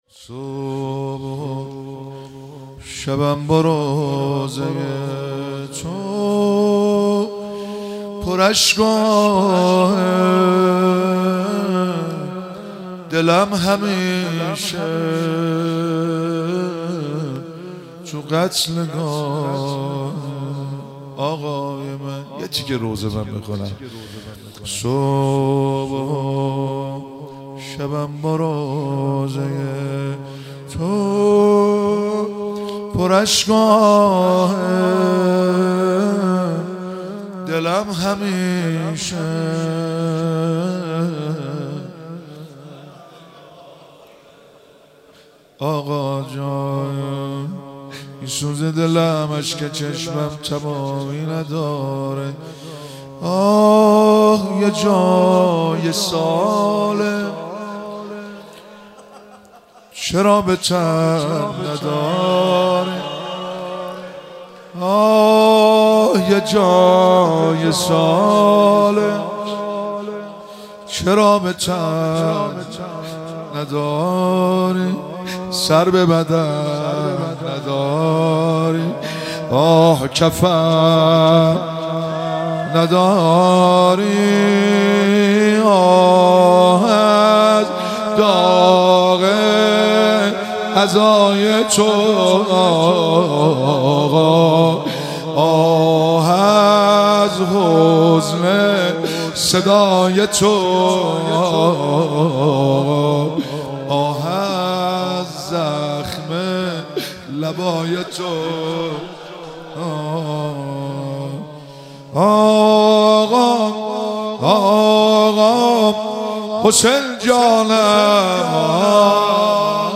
مداحان:
زمزمه - صبحُ شبم با روضه تو پر اشک و آه